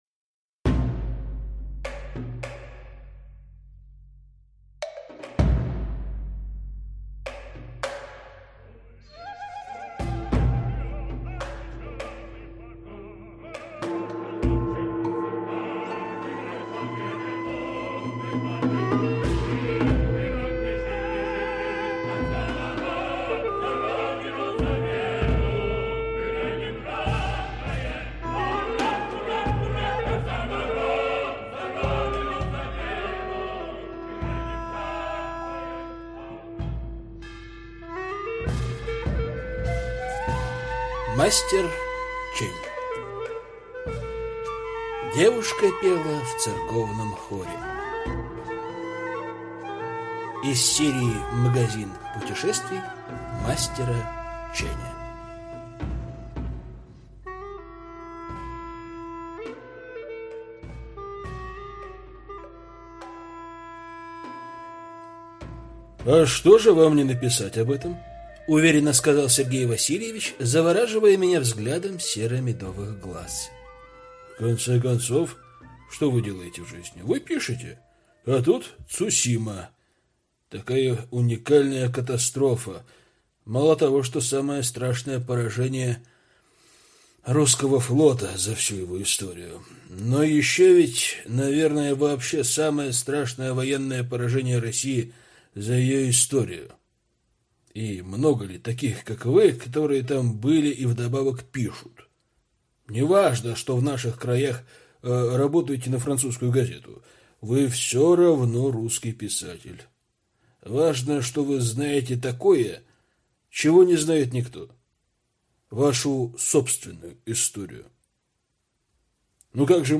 Аудиокнига Девушка пела в церковном хоре | Библиотека аудиокниг